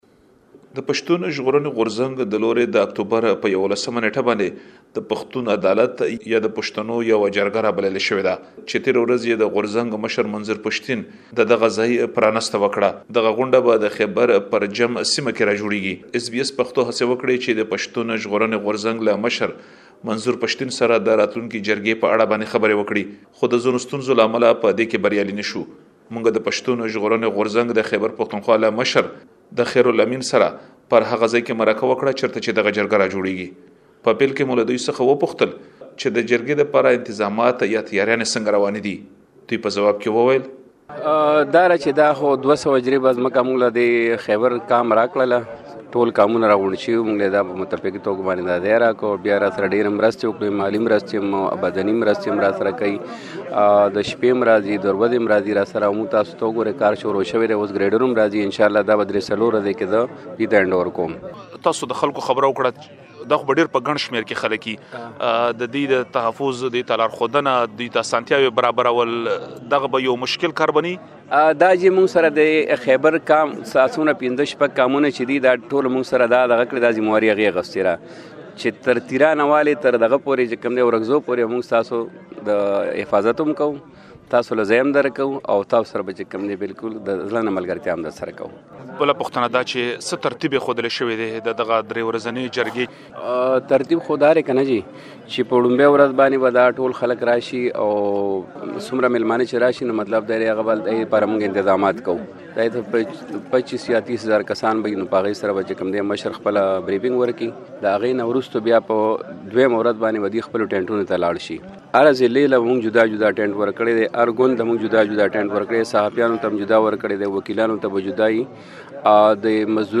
لا ډېر معلومات په ترسره شوې مرکې کې اورېدلی شئ.